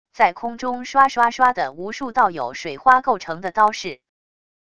在空中唰唰唰地无数道有水花构成的刀式wav音频